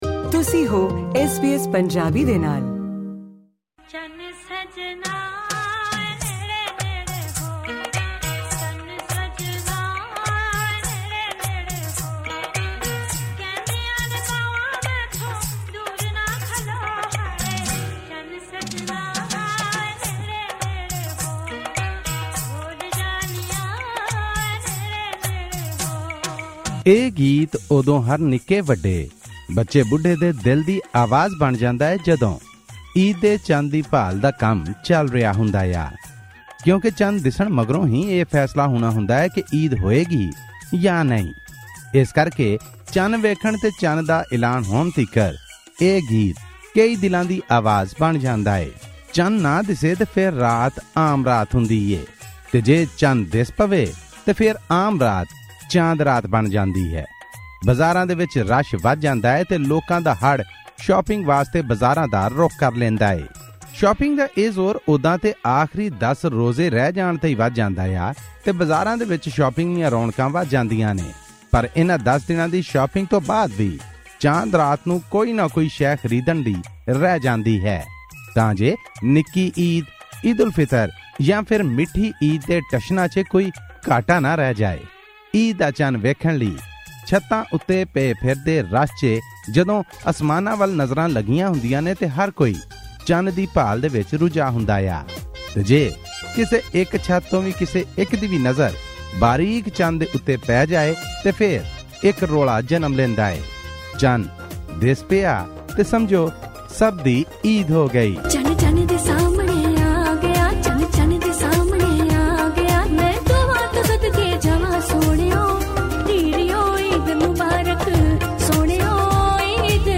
Special report: Eid al-Fitr celebrations in Pakistan